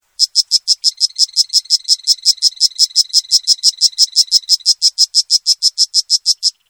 Dart Frog Calls